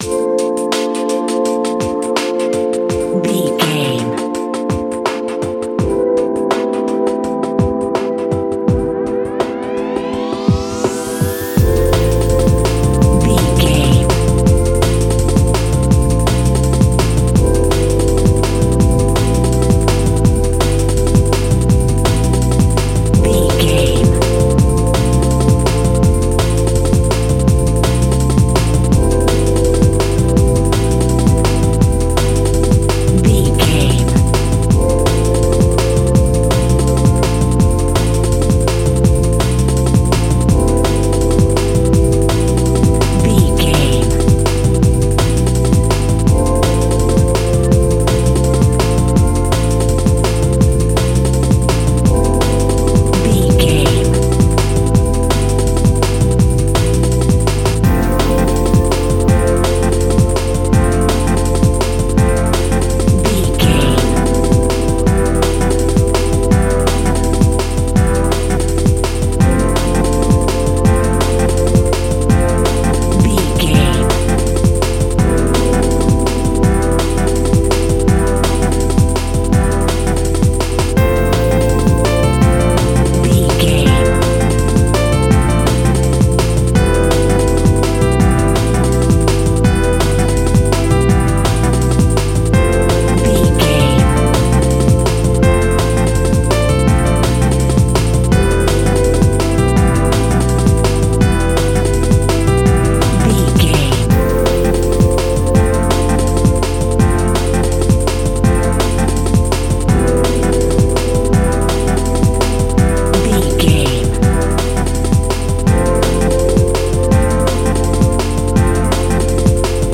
Epic / Action
Fast paced
Ionian/Major
futuristic
frantic
energetic
driving
drum machine
synthesiser
bass guitar
electronic
break beat music
synth bass
synth lead
robotic